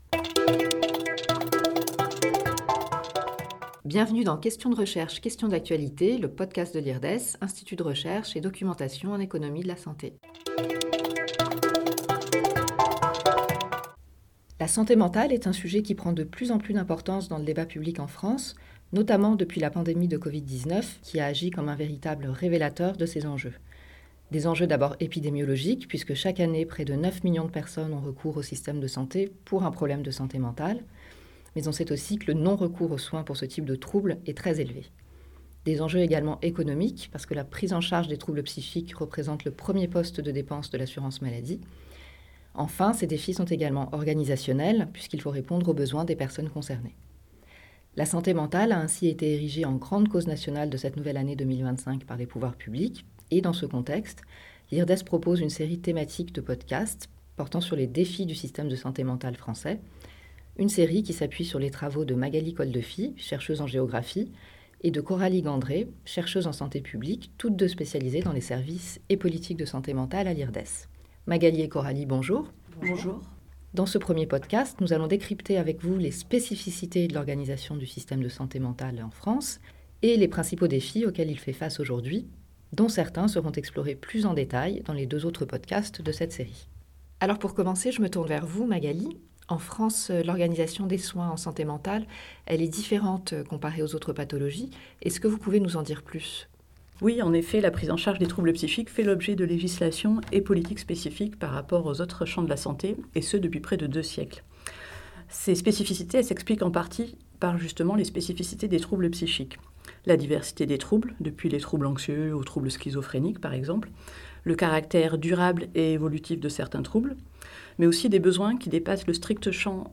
sont interrogées sur cette question d'actualité, qui fait l'objet d'une série thématique de trois podcasts. Ce premier épisode est consacré au décryptage de l'organisation spécifique du système de santé mentale français, qui se distingue des autres pays occidentaux et des autres champs de la santé, et aux principaux défis auxquels ce système doit faire face aujourd'hui.